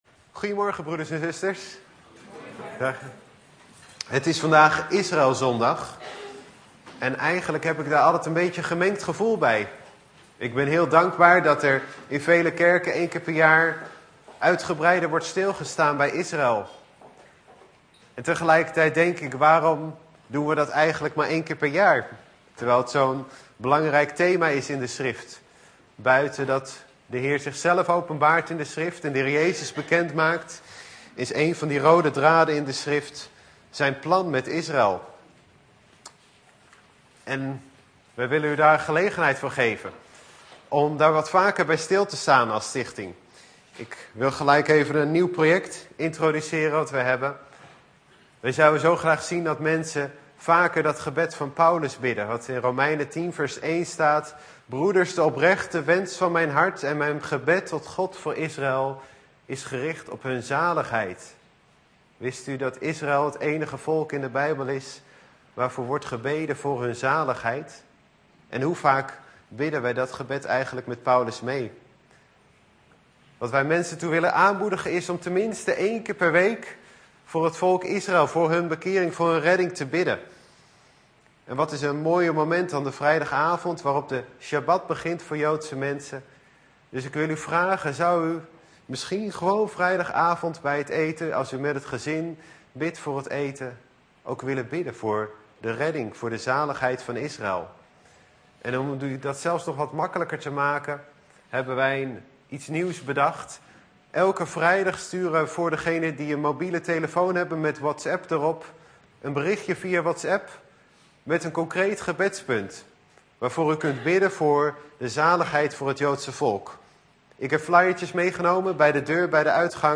In de preek aangehaalde bijbelteksten (Statenvertaling)Genesis 22:1-191 En het geschiedde na deze dingen, dat God Abraham verzocht; en Hij zeide tot hem: Abraham!